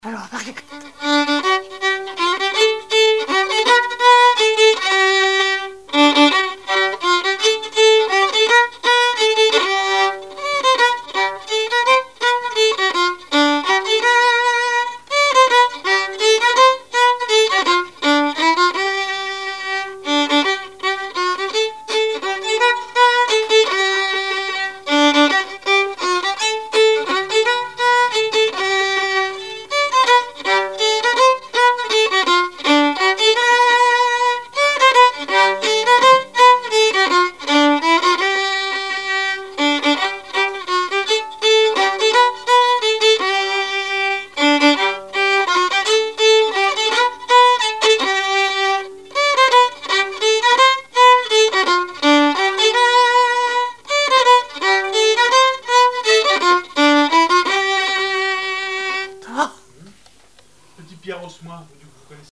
Chants brefs - A danser
danse : mazurka
Répertoire musical au violon
Pièce musicale inédite